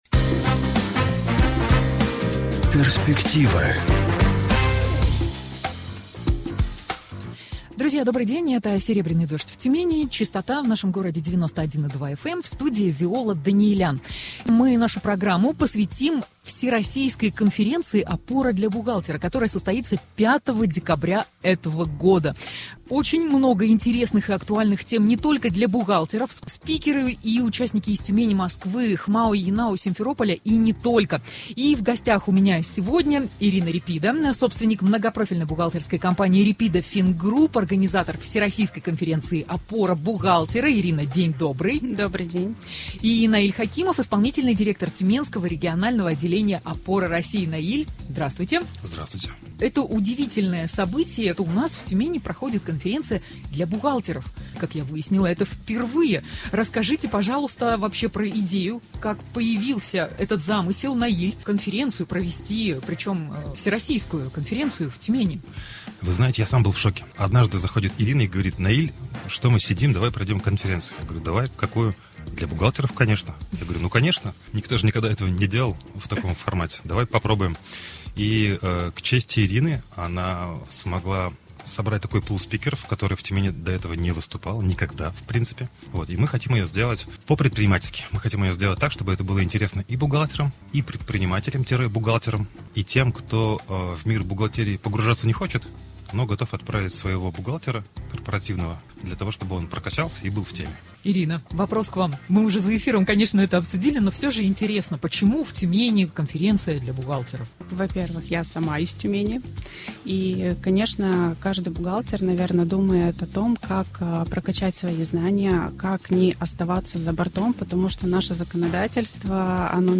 Опора бухгалтера в студии